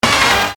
Hit 008.wav